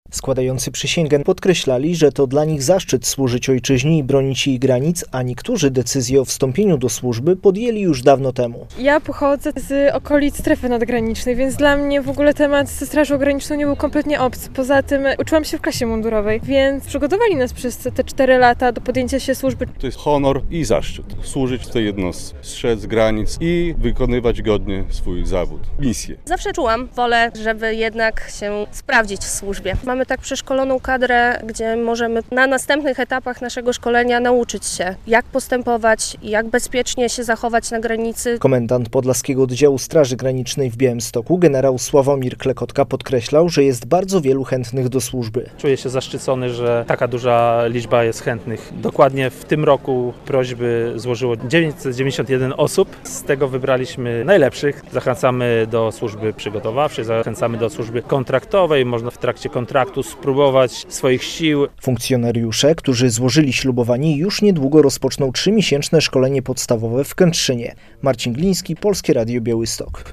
Zobowiązali się "strzec nienaruszalności granicy państwowej Rzeczypospolitej Polskiej, nawet z narażeniem życia". 64 nowych strażników granicznych złożyło w piątek (26.09) uroczyście ślubowanie w Białymstoku.
Komendant Podlaskiego Oddziału Straży Granicznej w Białymstoku generał Sławomir Klekotka mówił, że jest bardzo wielu chętnych do służby.